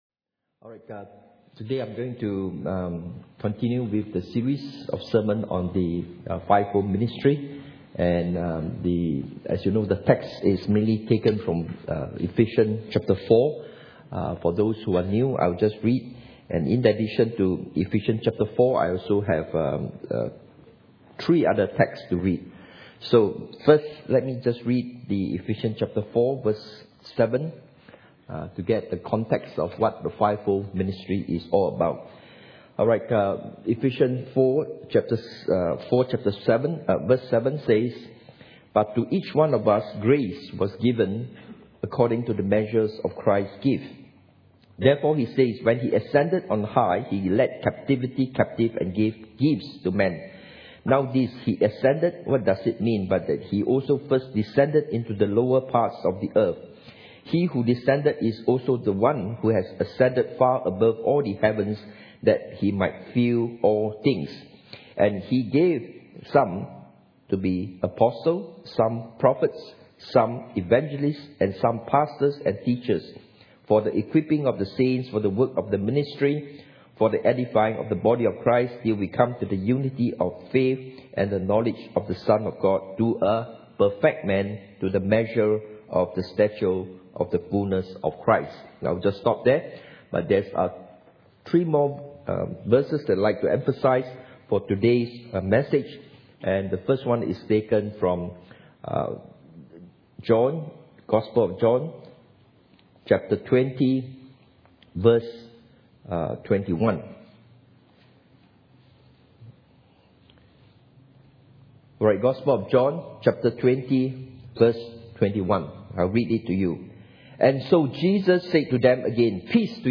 The 5 Fold Gifts Service Type: Sunday Morning « The 5 Fold Gifts